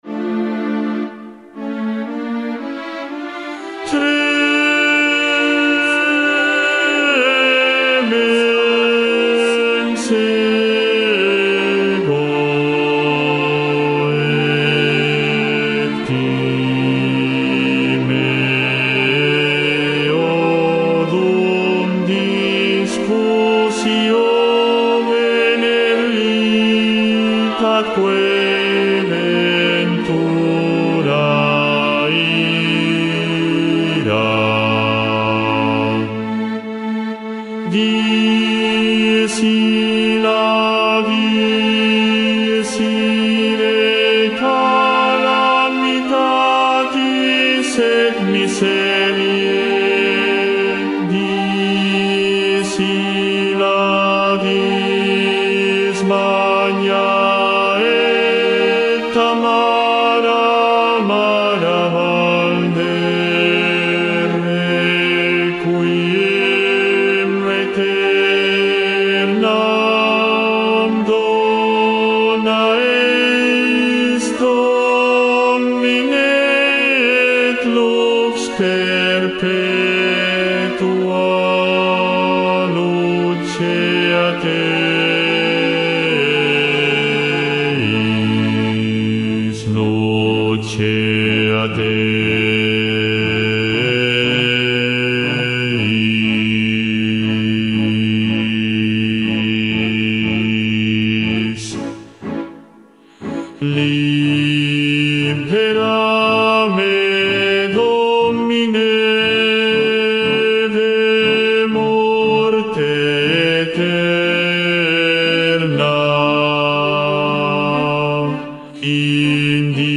Bajo I
6.-Libera-me-BAJO-I-VOZ.mp3